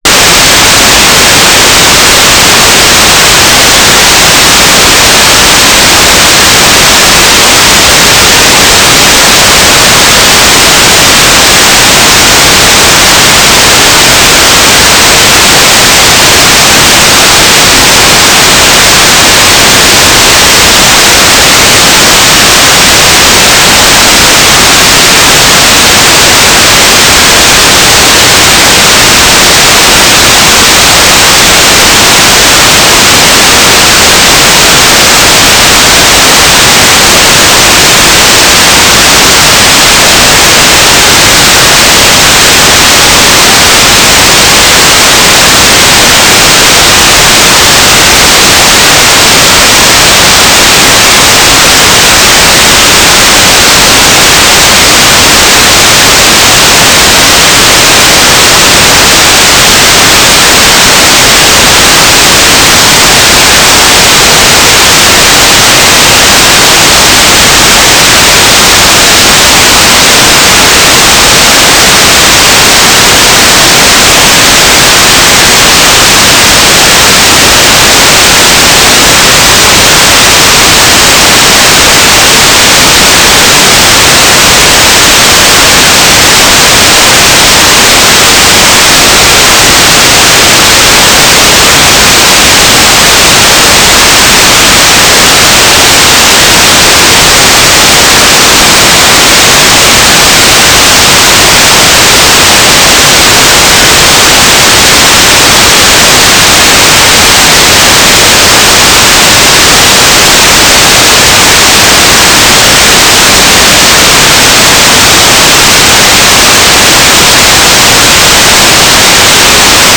"waterfall_status": "without-signal",
"transmitter_description": "AX.25 beacon",
"transmitter_mode": "FSK",